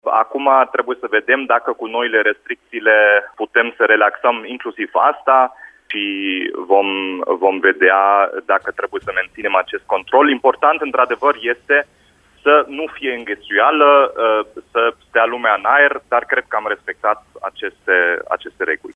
Primarul Dominic Fritz a declarat, la Radio Timișoara, că această măsură a fost luată în contextul reglementărilor aflate în vigoare în momentul organizării târgului.
Dominic-Fritz-certificat-verde.mp3